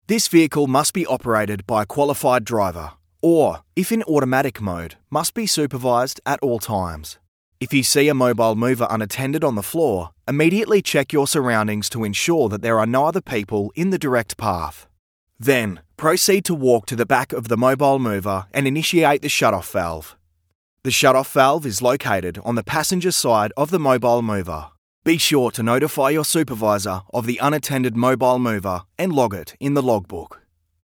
Male
English (Australian)
Adult (30-50)
My voice although very versatile has been described as warm, engaging, Aussie slang, natural, professional, confident, strong, professional and friendly to name a few.
E-Learning
1203Elearning_Script.mp3